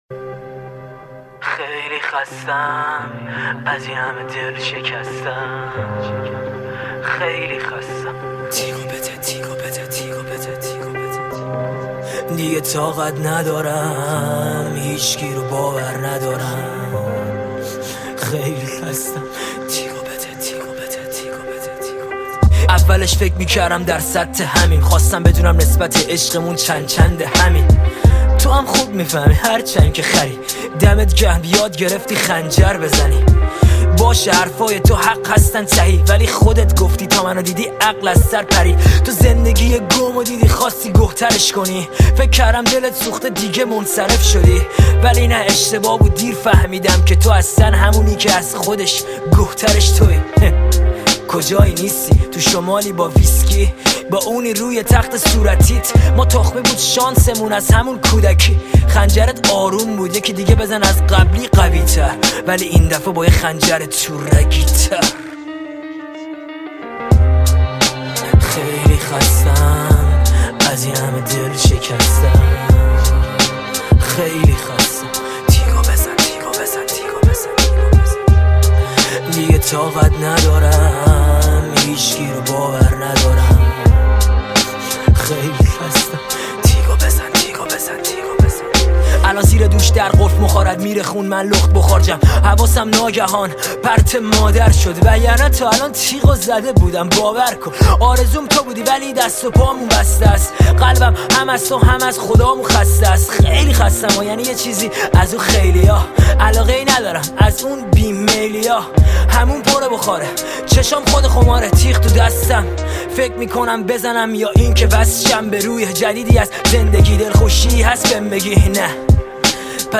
• آهنگ جدید ~ ریمیکس